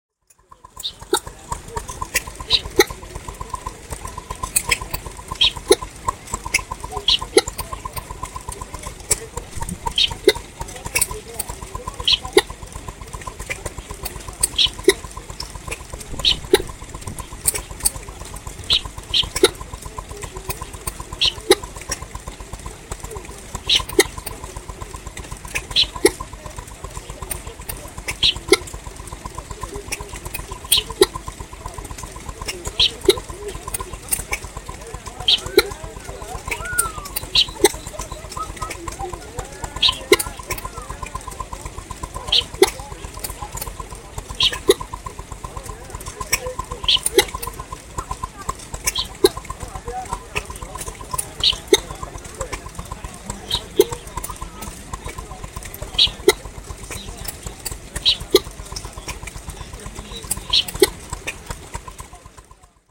Stationery engines recorded at the Malvern Autumn Show 2021, a display of vintage stationary engines at full throttle! Unfortunately I only had my phone with me, so this is a mono recording.